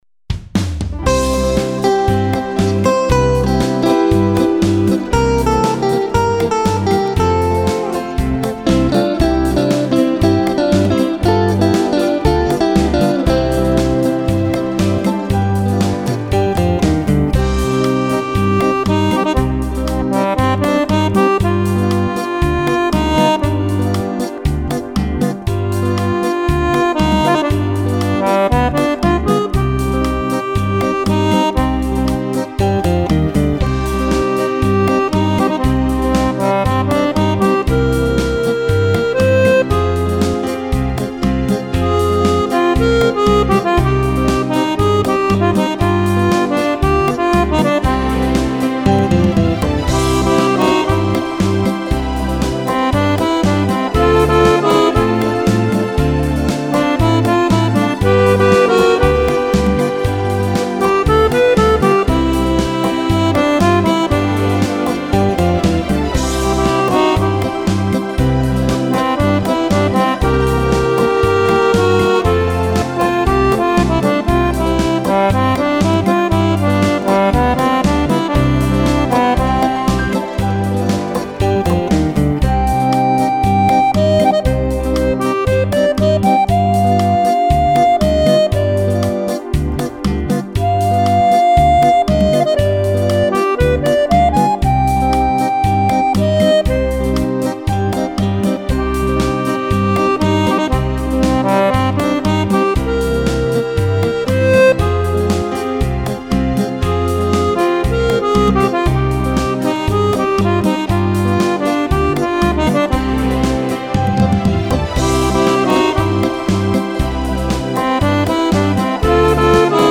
Beguine per Fisarmonica